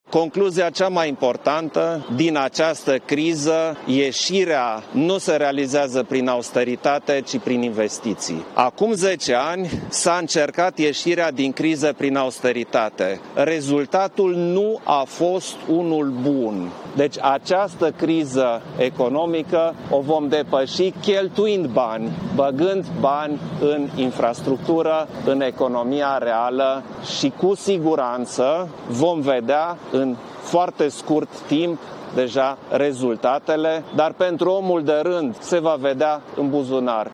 Președintele Klaus Iohannis a spus că este concluzia la nivelul Uniunii Europene, după cel mai recent euro-summit: